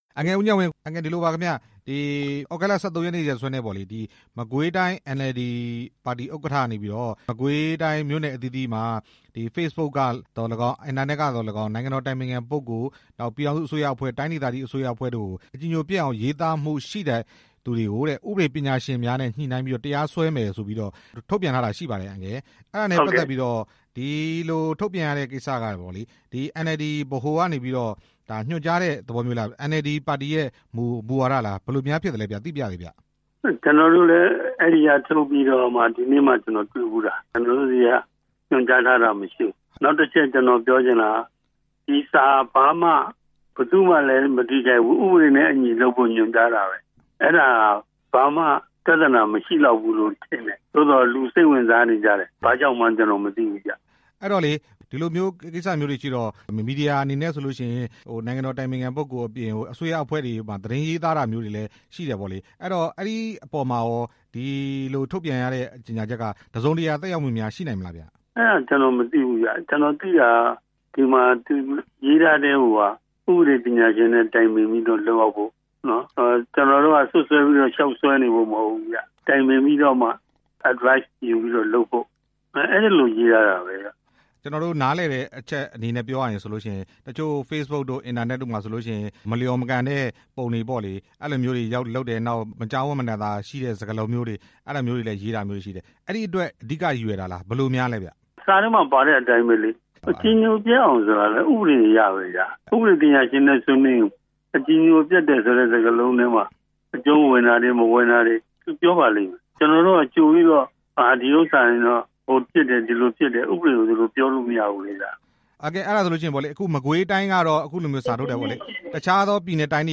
မကွေးတိုင်း NLD ပါတီရဲ့ ထုတ်ပြန်ကြေညာချက် ဦးဉာဏ်ဝင်းနဲ့ မေးမြန်းချက်